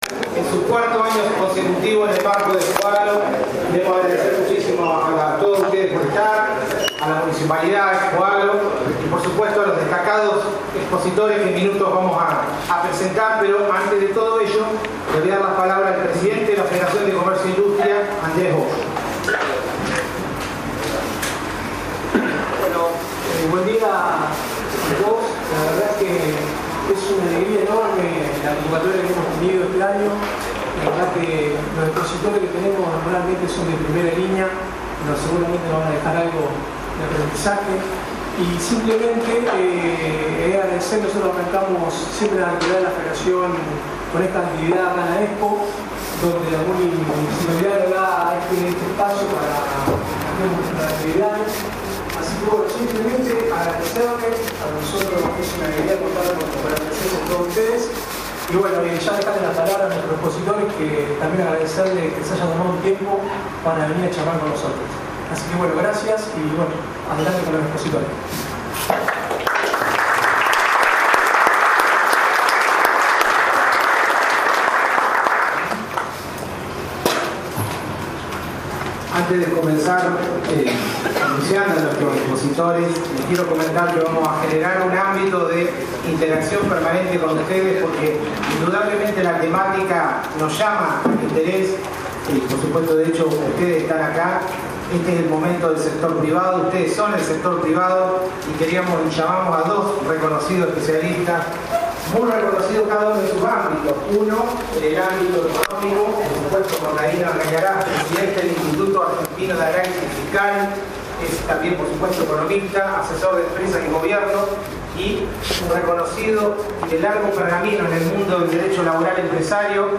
El encuentro concluyó con un espacio abierto de preguntas e intercambio con el público, instancia que permitió profundizar algunos conceptos y aportar nuevas miradas sobre un tema que sin dudas se mantiene en el centro de la agenda económica del país.
Acto de Apertura